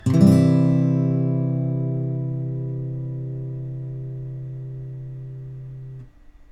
私が扱うのはアコースティックギターです。